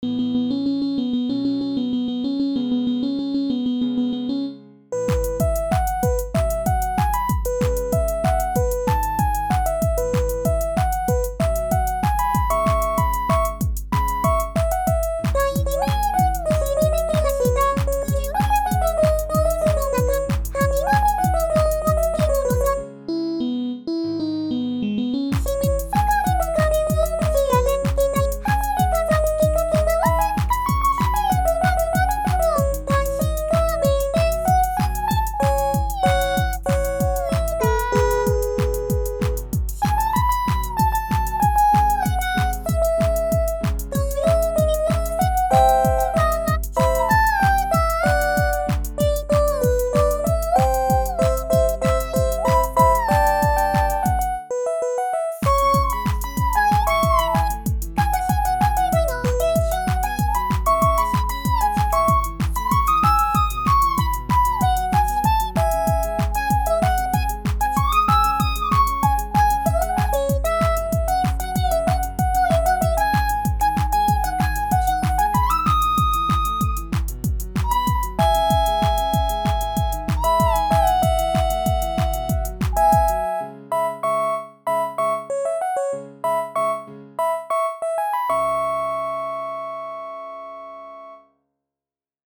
出于好玩，我去学了学flstudio和VOCALOID，然后这是我弄出来的第一个作品，比较粗糙，希望以后能做得更好吧！